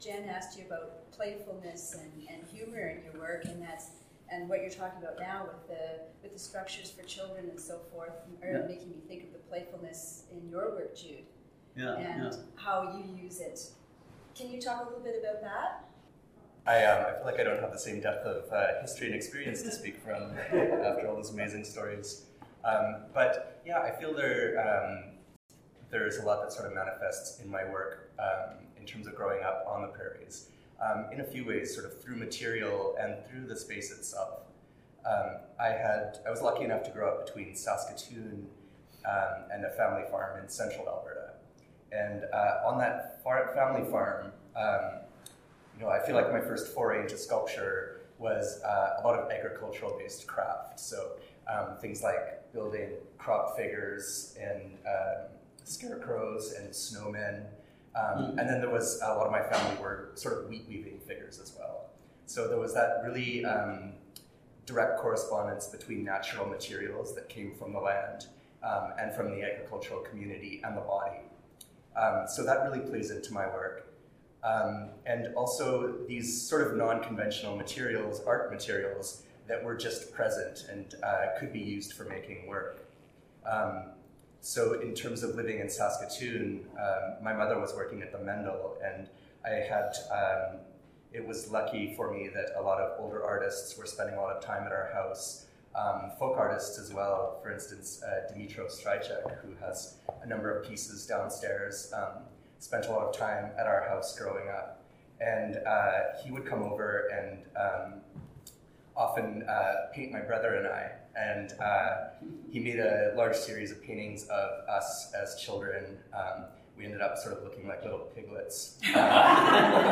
Entrevue avec l’artiste.